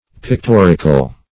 Pictorical \Pic*tor"ic*al\